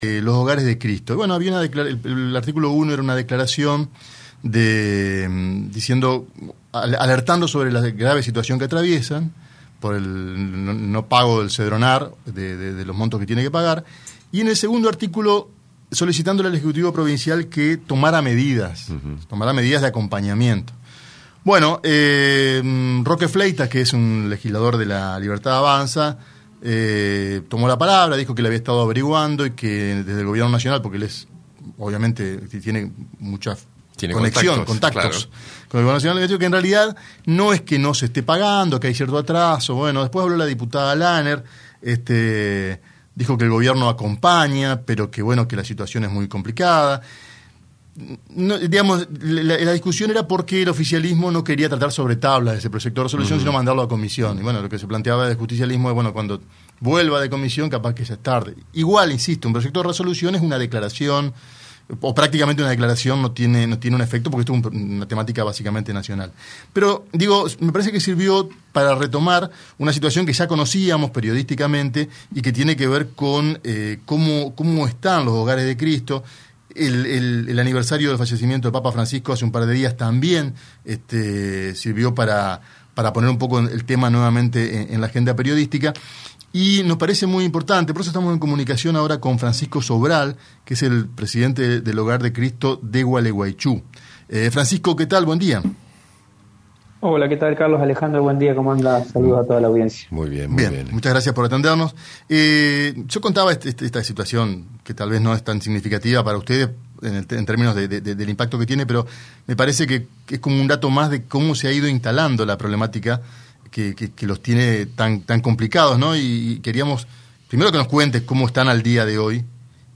en diálogo con el programa Buen Día